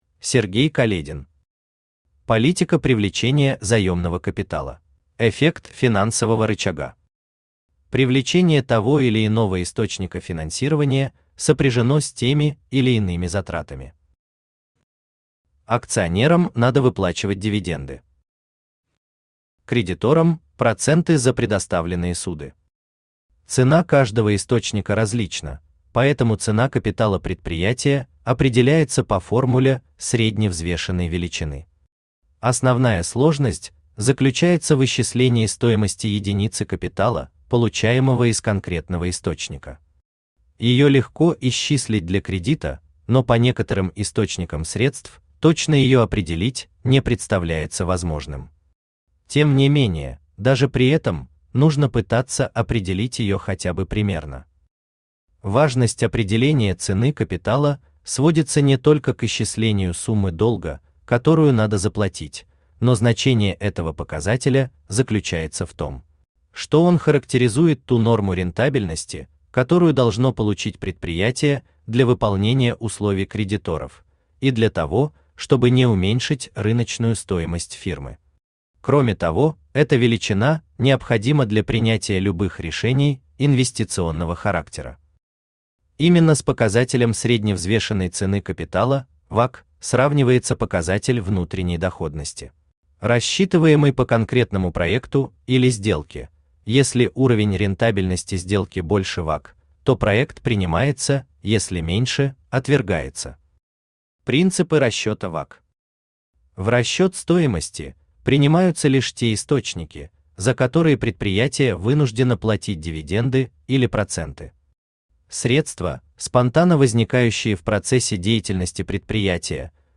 Aудиокнига Политика привлечения заёмного капитала Автор Сергей Каледин Читает аудиокнигу Авточтец ЛитРес.